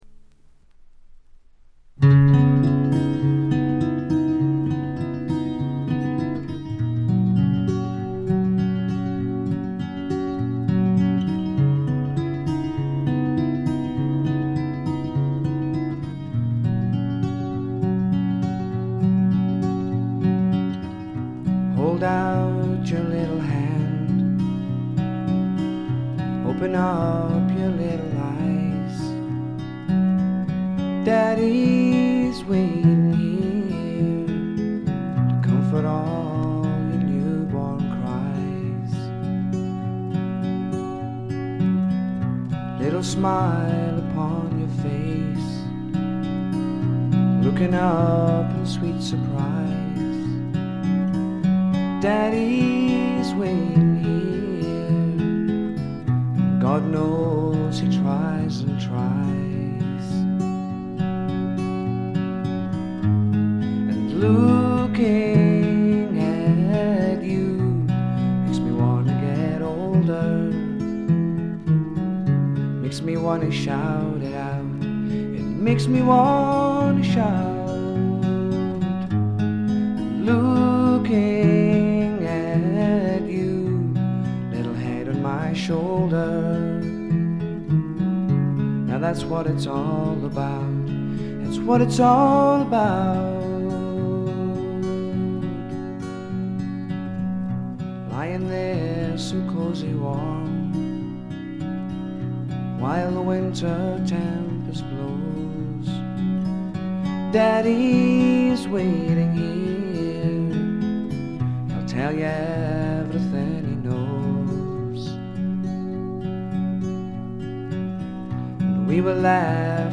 ほとんどノイズ感無し。
彼の特徴である噛みしめるように紡ぎ出すあたたかな歌声を心ゆくまで味わってください。
試聴曲は現品からの取り込み音源です。